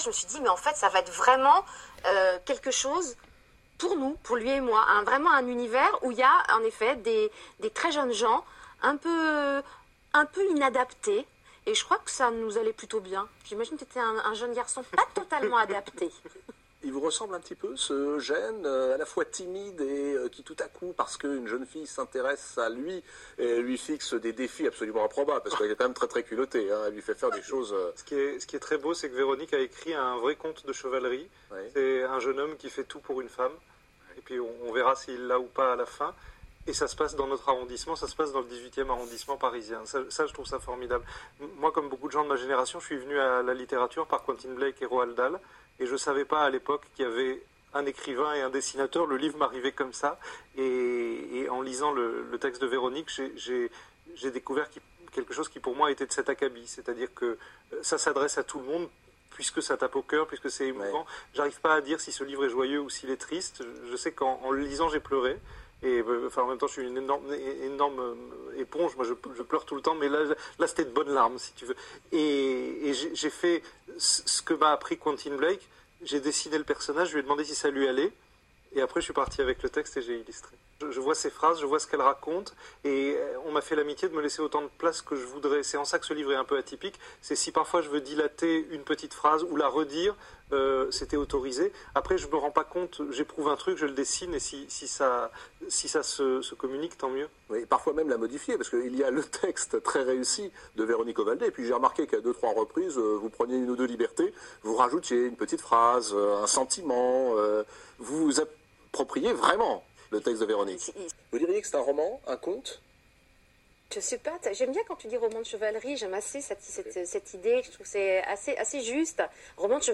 Et voici des extraits d’une émission où les deux auteurs, qu’on sent très complices, parlaient de leur travail pour donner vie à cette histoire.